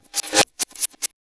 MAD138TAMB-R.wav